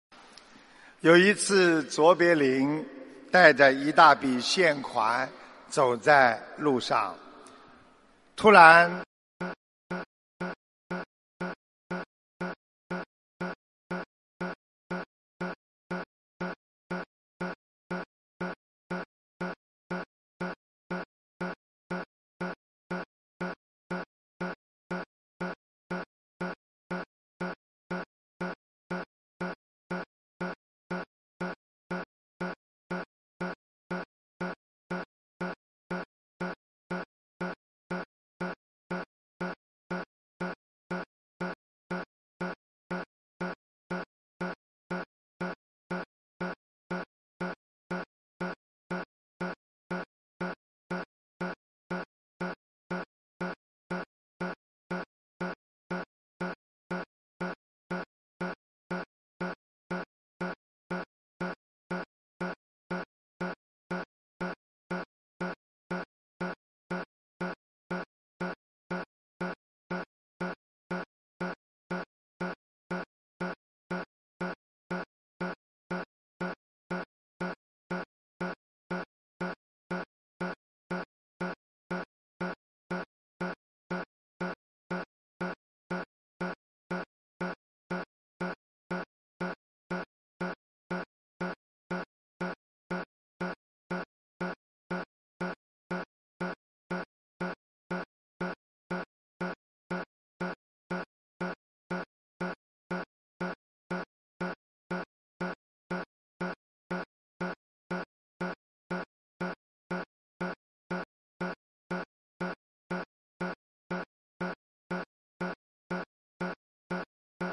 音频：卓别林智斗蒙面大盗·师父讲小故事大道理